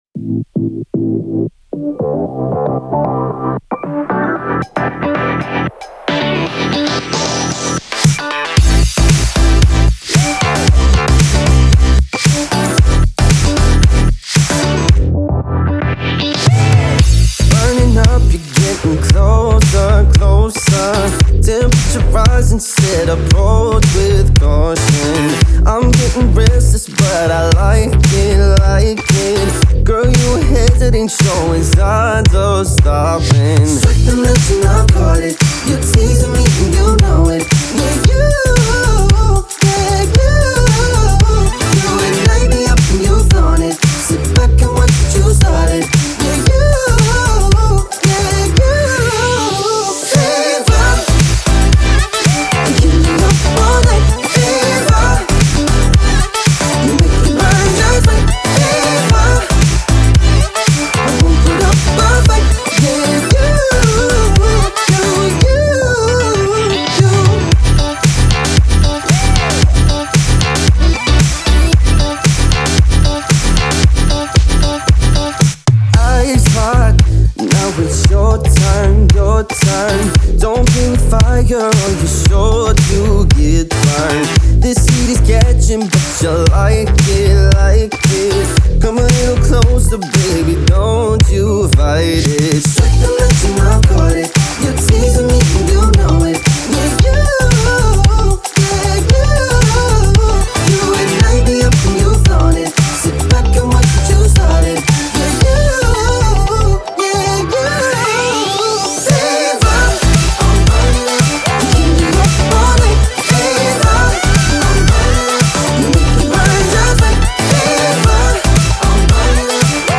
BPM114
Audio QualityPerfect (High Quality)
Commentaires[NU FUNK/DANCE]